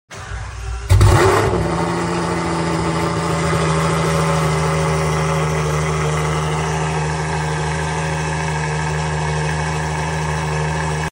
🔥 Ferrari 812 GTS 2022 – Cold Start V12 Symphony 🔊 6.5L Naturally Aspirated V12 thundering to life with pure Italian passion. No turbos, no filters — just raw horsepower and emotion.